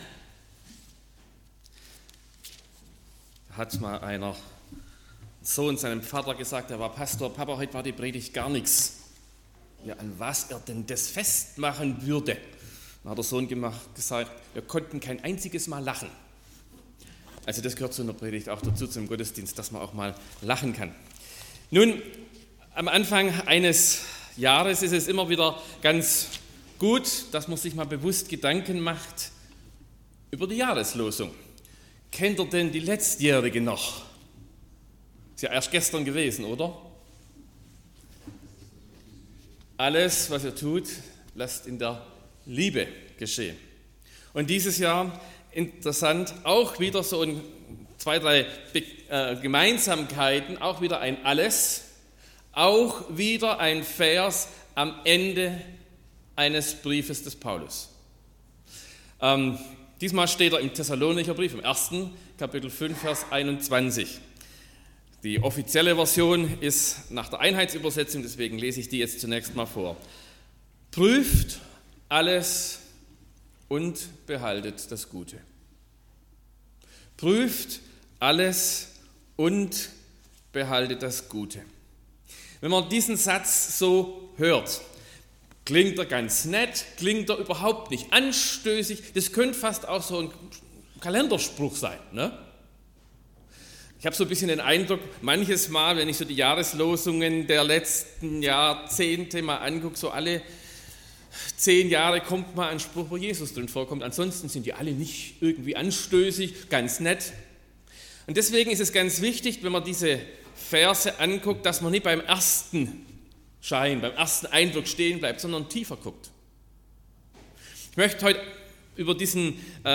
01.01.2025 – Gottesdienst
Predigt (Audio): 2025-01-01_Prueft_alles_und_behaltet_das_Gute_.mp3 (23,3 MB)